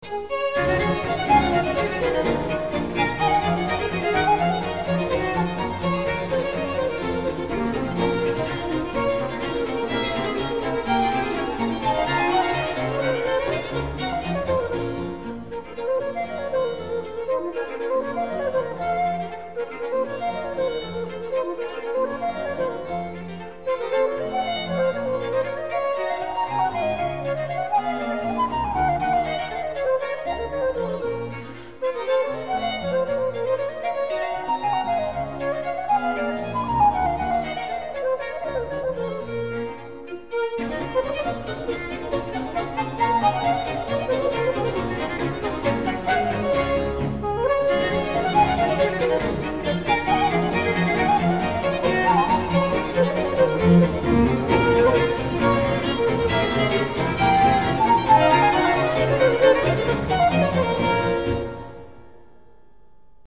Bach Suite pour orchestre n°2 I Barocchisti Arts
bach suite flute bouree barocchisti.mp3